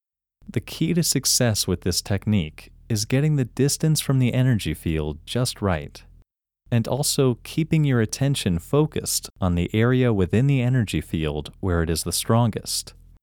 IN – First Way – English Male 7
IN-1-English-Male-7.mp3